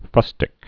(fŭstĭk)